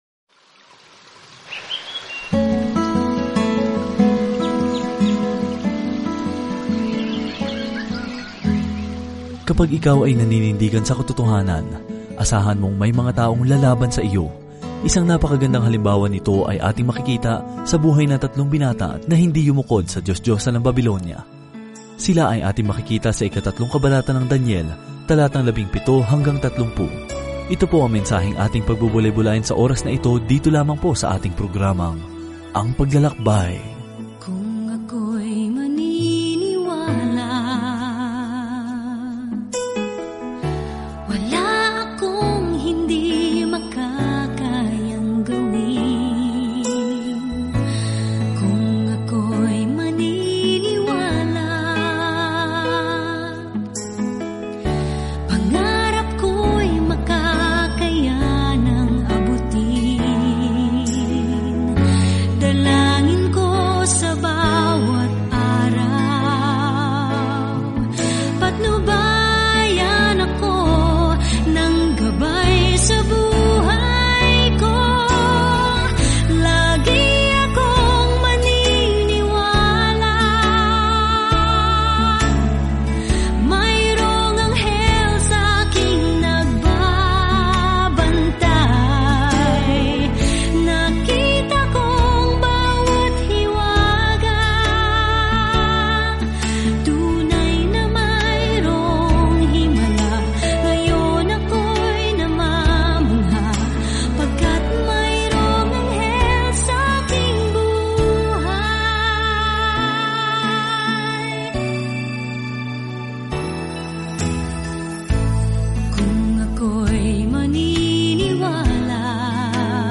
Banal na Kasulatan Daniel 3:7-30 Araw 8 Umpisahan ang Gabay na Ito Araw 10 Tungkol sa Gabay na ito Ang aklat ni Daniel ay parehong talambuhay ng isang taong naniwala sa Diyos at isang makahulang pangitain kung sino ang mamamahala sa daigdig. Araw-araw na paglalakbay kay Daniel habang nakikinig ka sa audio study at nagbabasa ng mga piling talata mula sa salita ng Diyos.